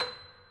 Steinway_Grand
b6.mp3